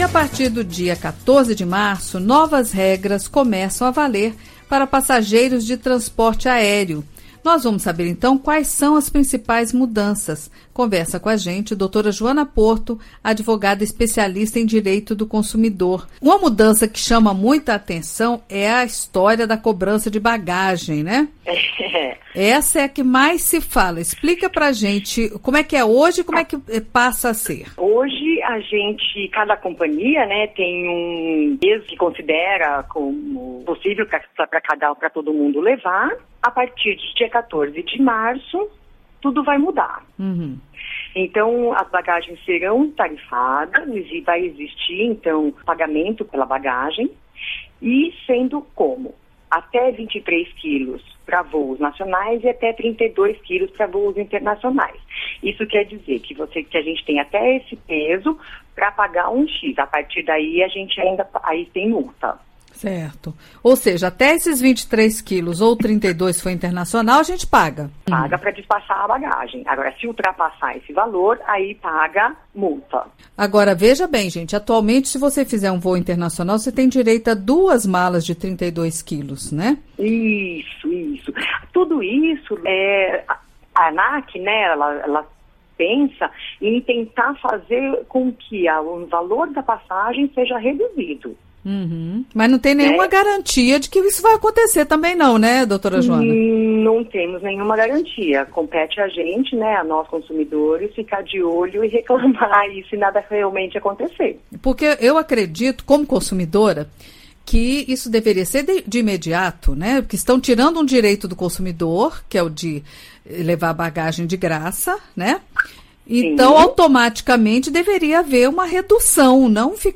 Entrevista: Saiba as novas regras das empresas aéreas para passageiros